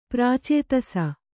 ♪ prācētasa